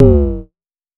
Vermona Perc 08.wav